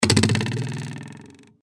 Gemafreie Sounds: Schusswaffen
mf_SE-1192-arrow_impact_1.mp3